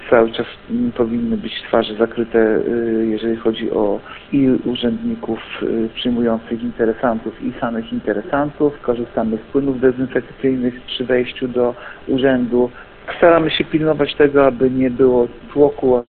Mówi wiceprezydent Ełku Artur Urbański.